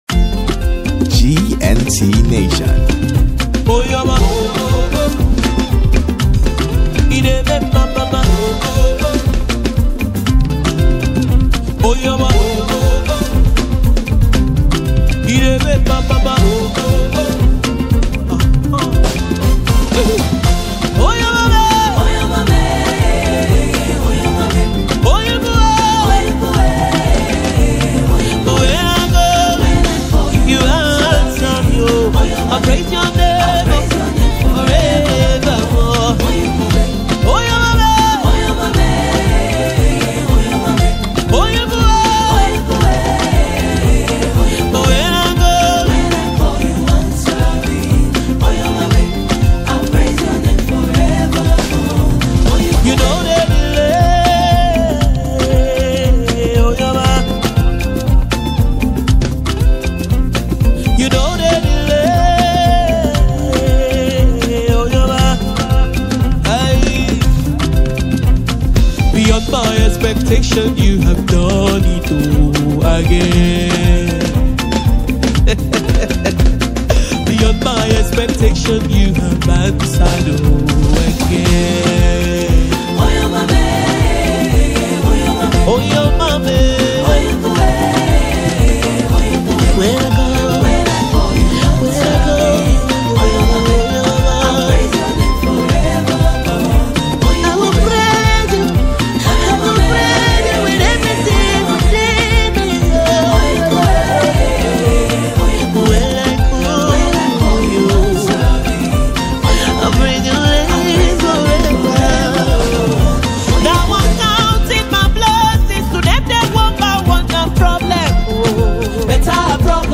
Nigerian gospel music
blend contemporary gospel with African cultural sounds